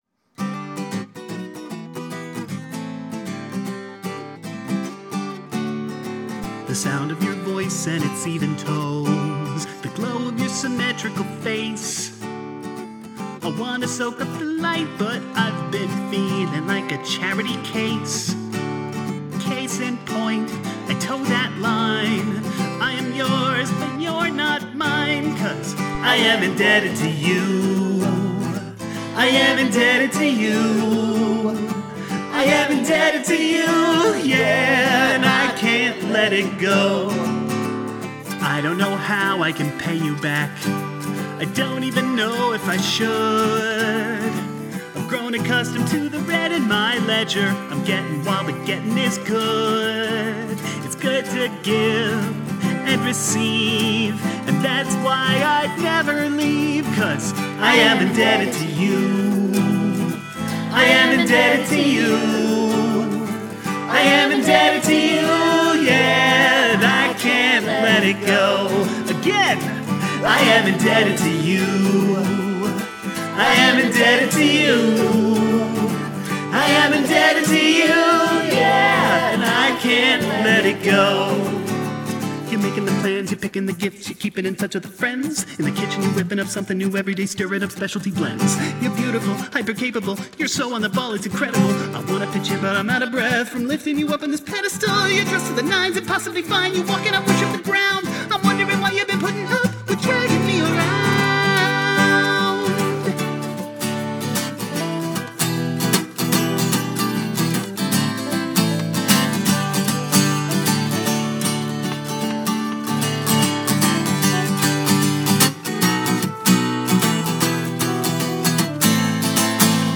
Around 3:09 the guitar gets pretty seriously out of time.
Weakness: kitschy, needs more belly singing